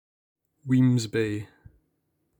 Wemyss Bay (/ˌwmz ˈb/
Wemyss_Bay_pronunciation.ogg.mp3